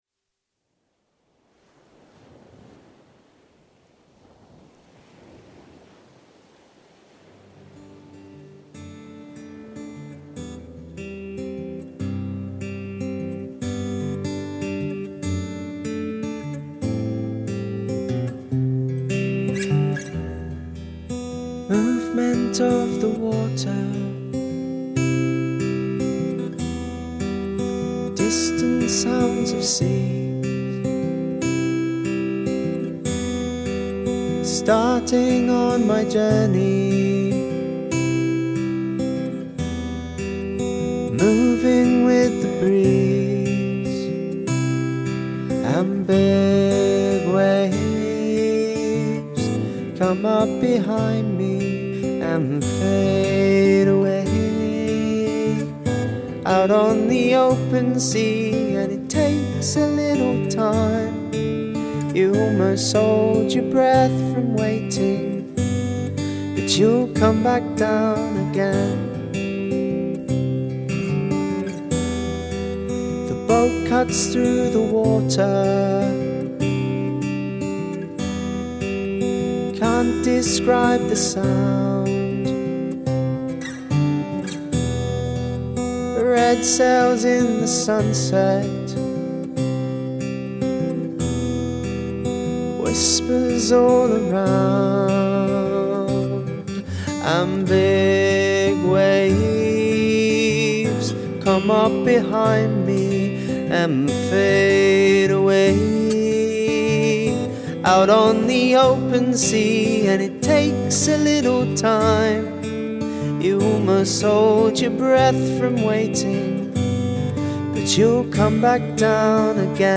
Once we’d written the song together, I took it into a music studio to record it.